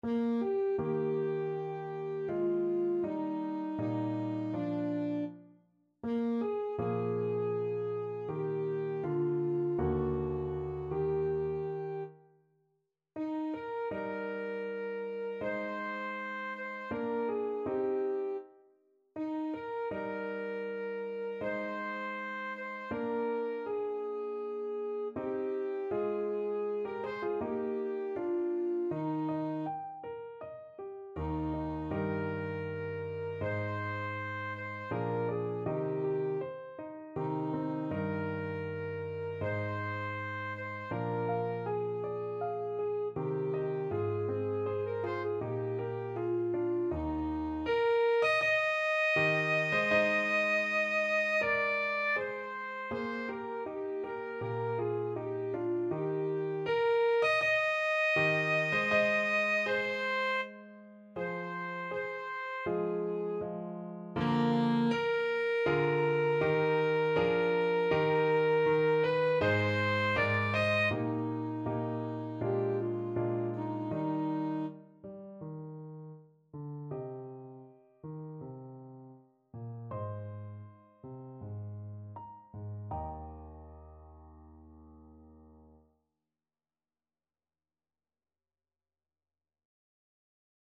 Alto Saxophone version
Alto Saxophone
4/4 (View more 4/4 Music)
Andante
Classical (View more Classical Saxophone Music)